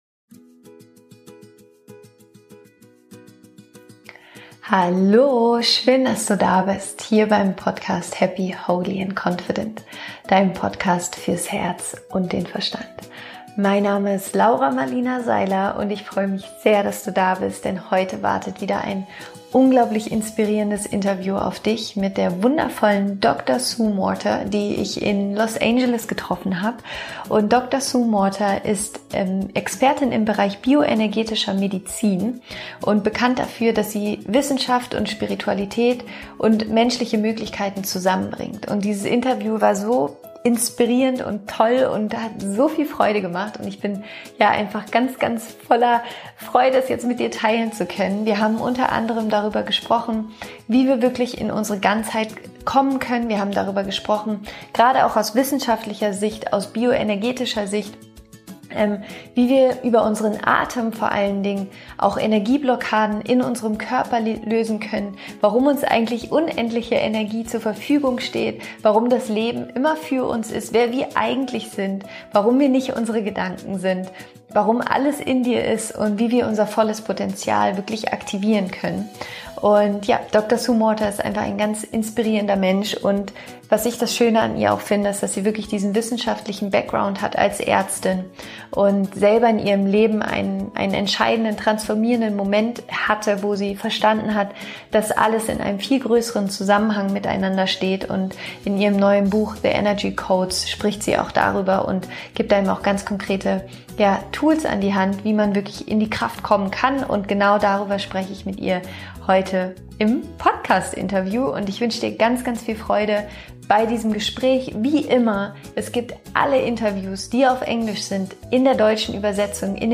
Wie du deine Probleme auf energetischer Ebene lösen kannst - Interview Special